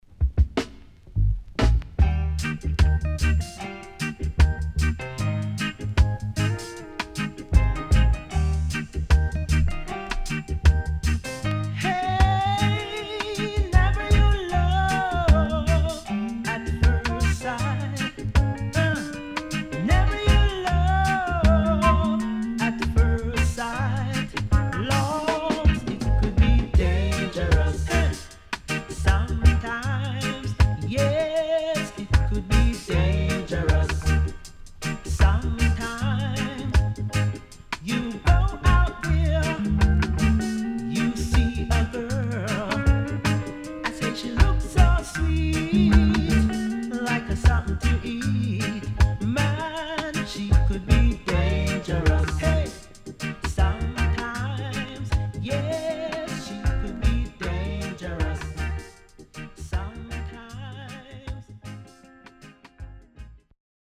HOME > Back Order [VINTAGE LP]  >  STEPPER